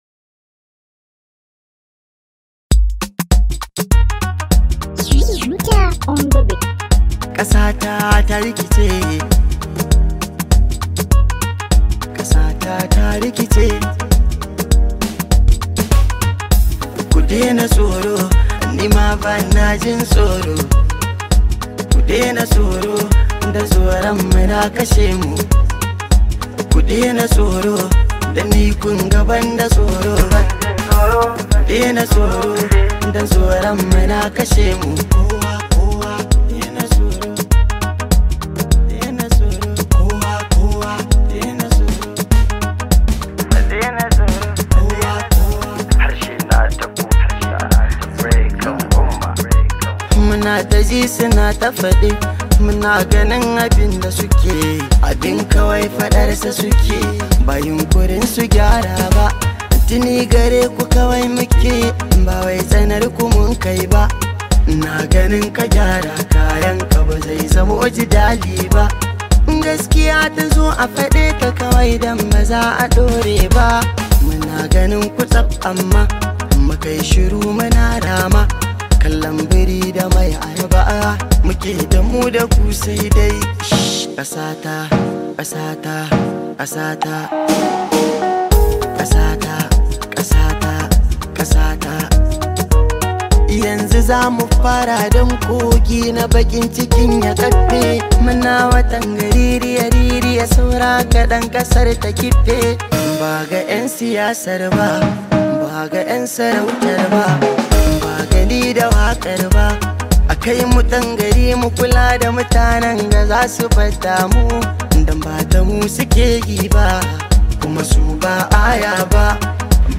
Hausa Singer
it comes with a lot of energy and positive Vibes